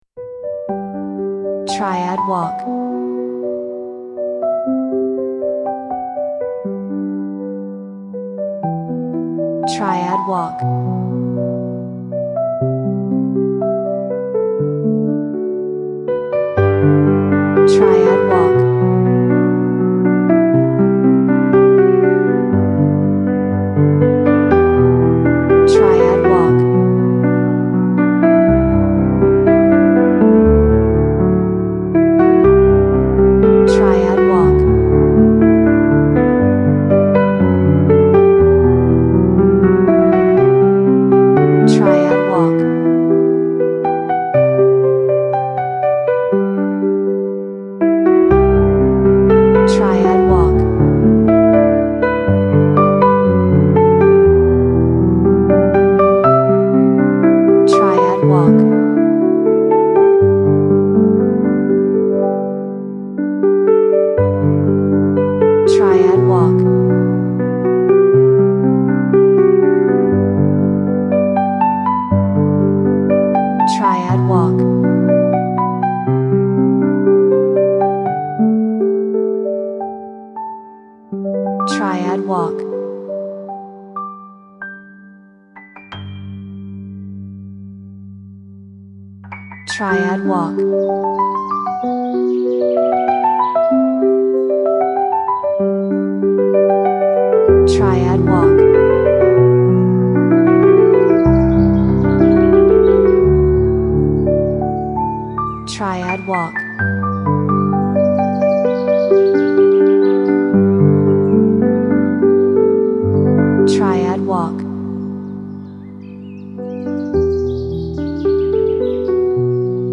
静かで平和な森の風景をイメージし、自然の美しさを感じさせる穏やかなピアノの旋律で表現した楽曲です。